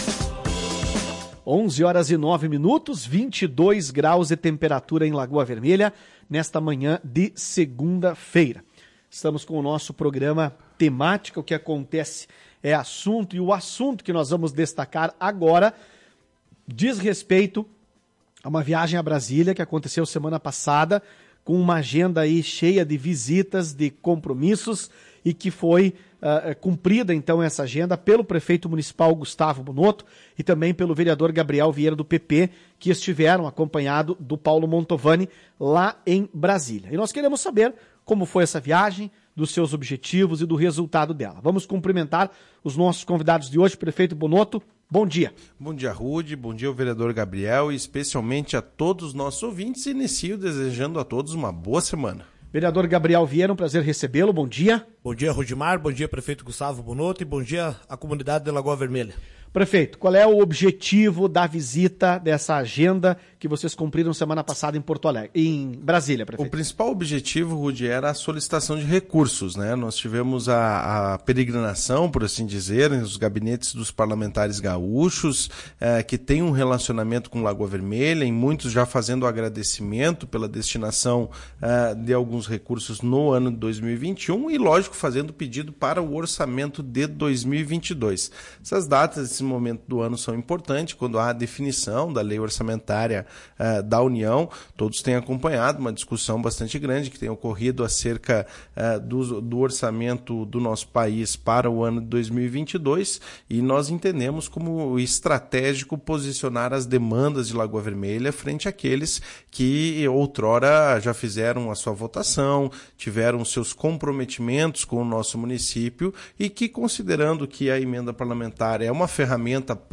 Na área da educação, a administração municipal pleiteia uma nova creche para Lagoa Vermelha, que deverá ser construída na área central de Lagoa Vermelha. Ouça a entrevista.